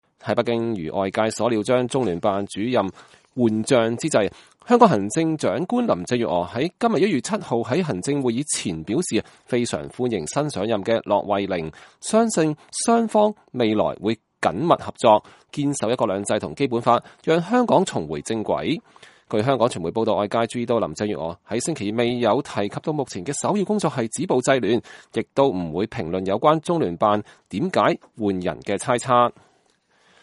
香港行政長官林鄭月娥2020年1月7日在記者會上講話。